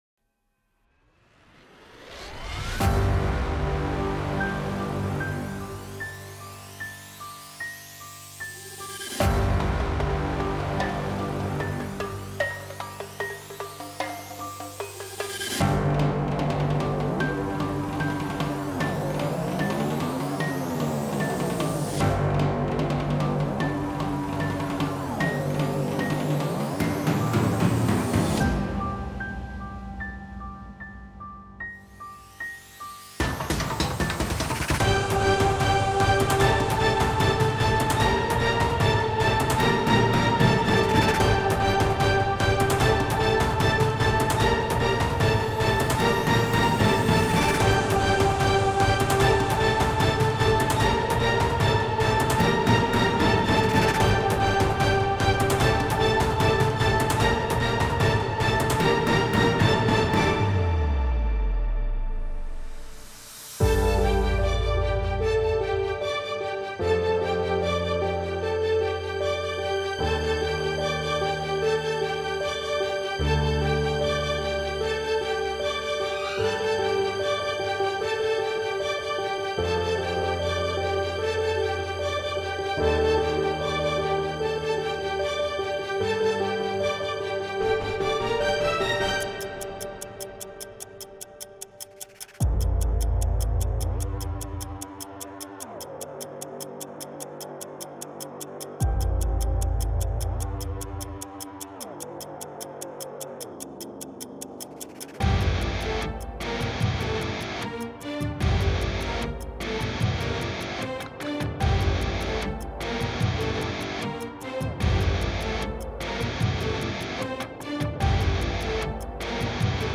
מוזיקת טריילר 🔥🔥🔥בביצוע שלי
תמיד אהבתי את הסגנון הזה אתמול היה פעם ראשונה שניסיתי על באמת לשבת על זה, וזה מה שיצא ה drums loops של נקסוס (לא אני בניתי את הלופים של התופים) .
משחק יפה עם כל הסינטים, היו קצת קטעים שהם הציקו וחפרו לי אבל זה לא מוריד מהרמה.